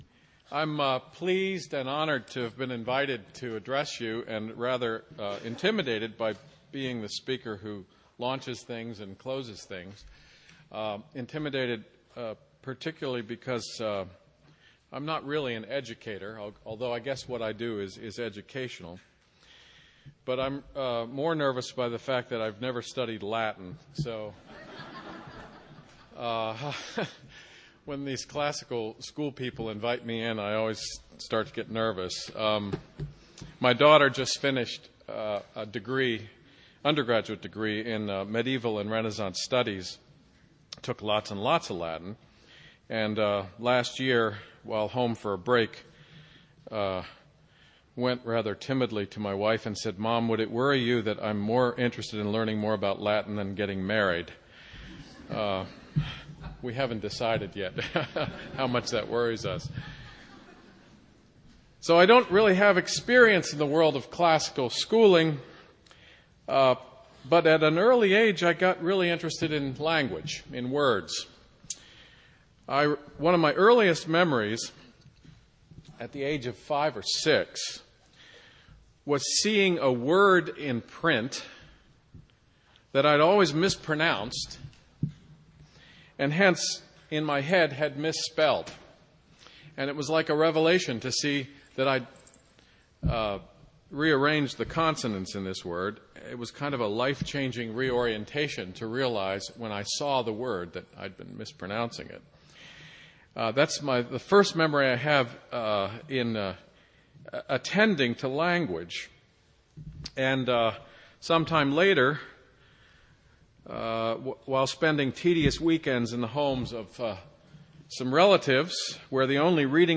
Plenary Talk, 0:55:01, Culture & Faith